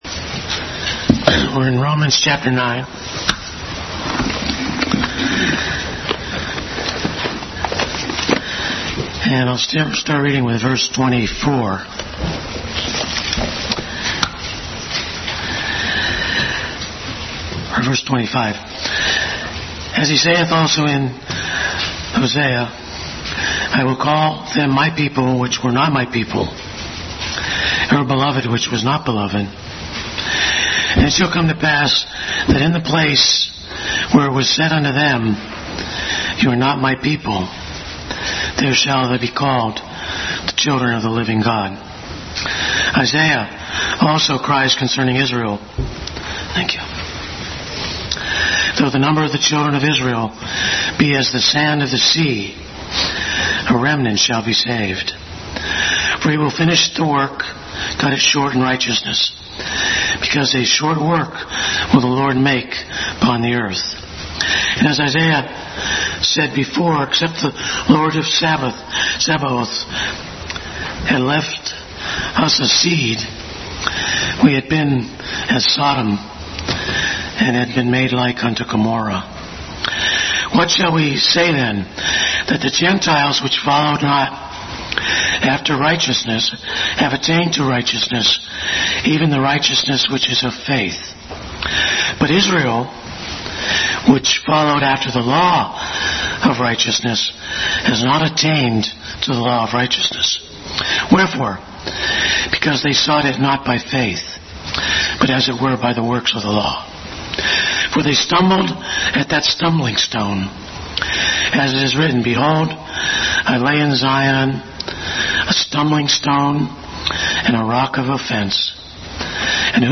Bible Text: Romans 9:25-33, John 7:46-52, Romans 9:22-23 | Adult Sunday School continued study in the book of Romans.